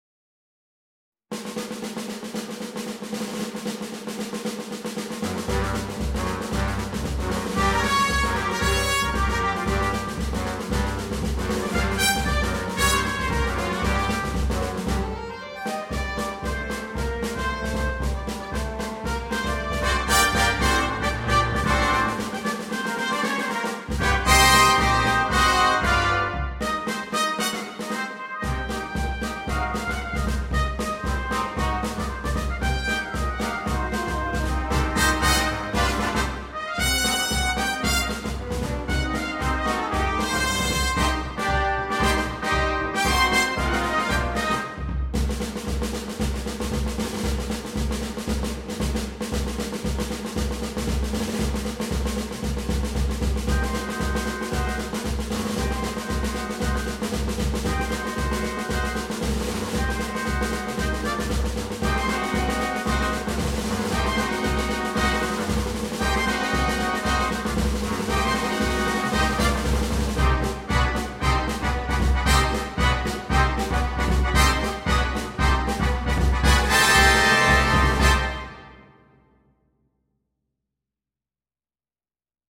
для смешанного состава.